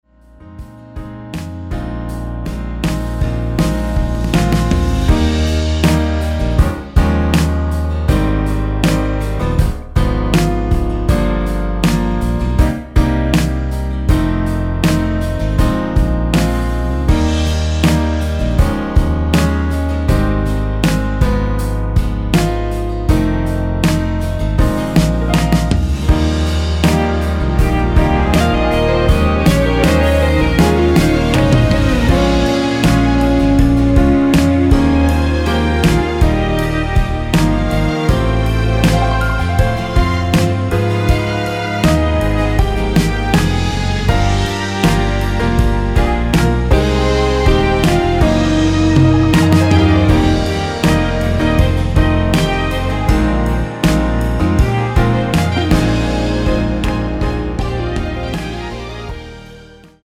원키에서(-1)내린 2절 삭제 MR 입니다.(미리듣기및 아래의 가사 참조)
엔딩이 페이드 아웃이라 라이브 하시기 좋게 엔딩을 만들어 놓았습니다.
Eb
앞부분30초, 뒷부분30초씩 편집해서 올려 드리고 있습니다.